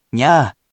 We’re going to show you the character(s), then you you can click the play button to hear QUIZBO™ sound it out for you.
In romaji, 「にゃ」 is transliterated as「nya」which sounds like「nyahh」.
Rather than the incorrect ‘✖nee-yah’, it is pronounced as simply ‘〇nyah’.